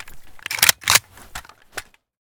aek971_unjam.ogg